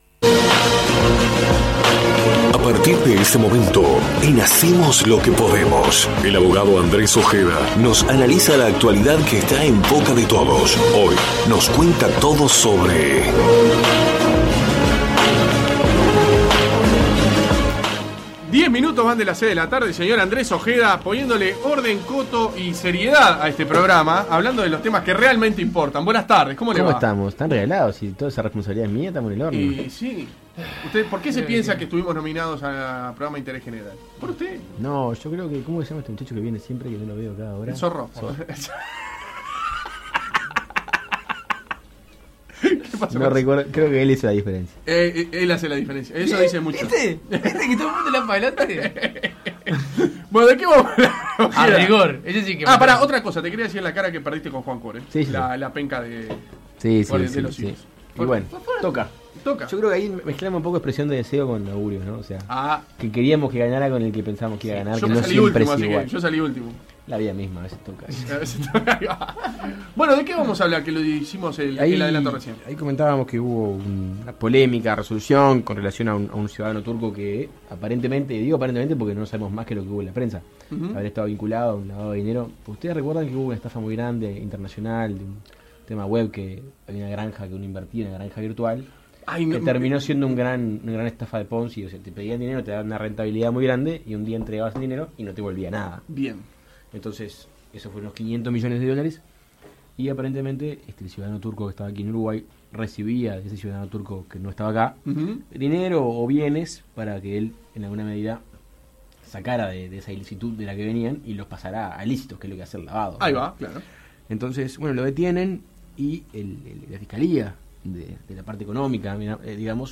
Este martes el reconocido Abogado Andrés Ojeda realizó su columna semanal en el programa Hacemos lo que podemos.